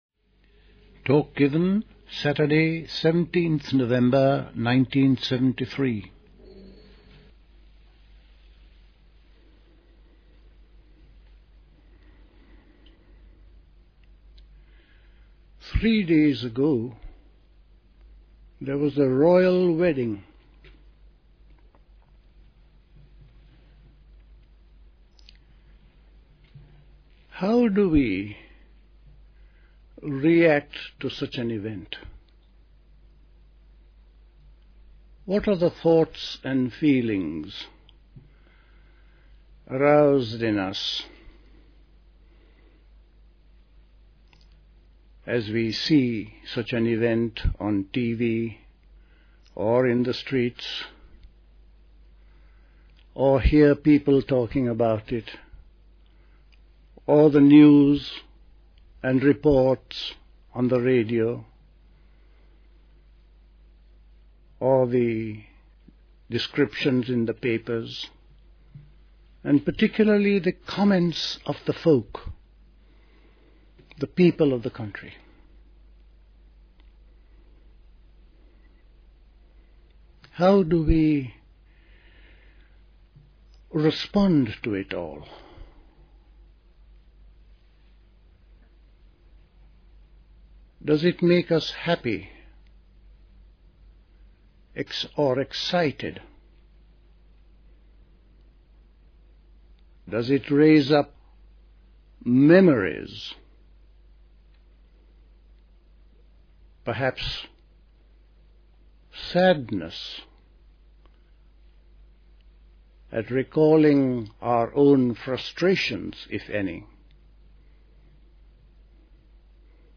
A talk given
at Dilkusha, Forest Hill, London on 17th November 1973